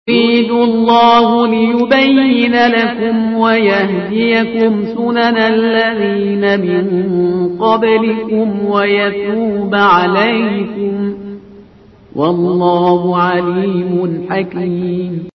🎤صوتی استاد مفسر